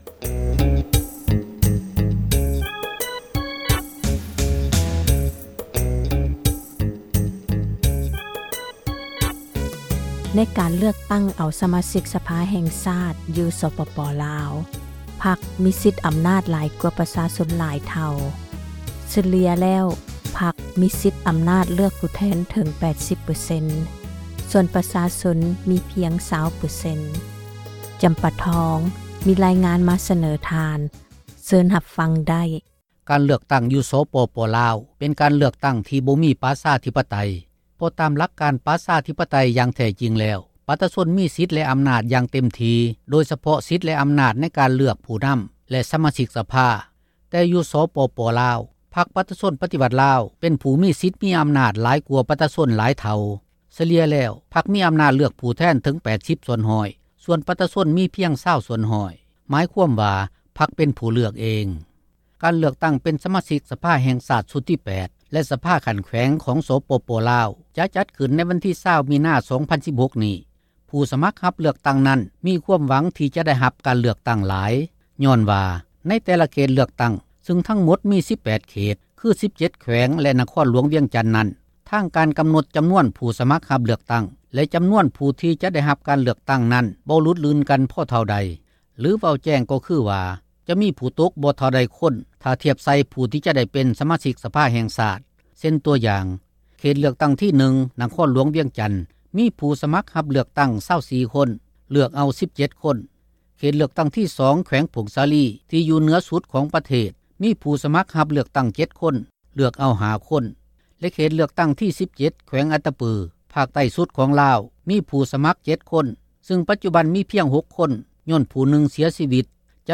Citizen Journalist